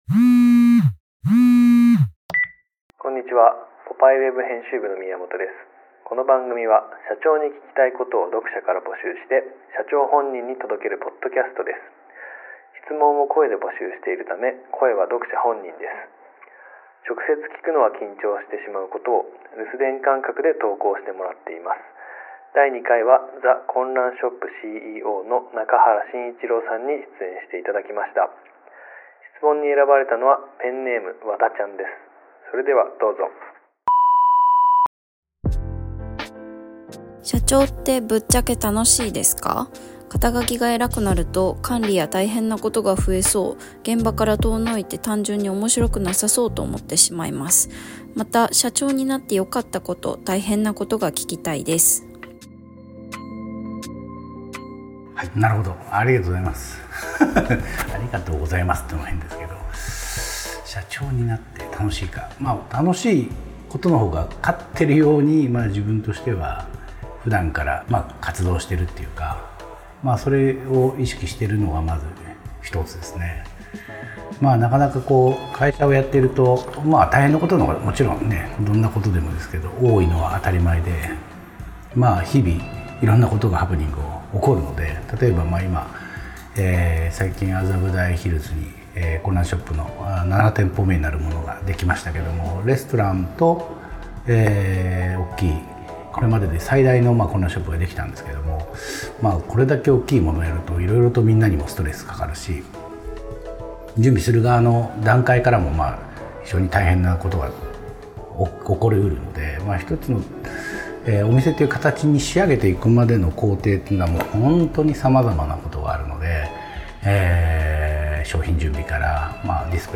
社長に聞きたいことを読者から募集して、社長に届けるポッドキャストがスタート！ 質問を音声で募集しているため、声は読者本人。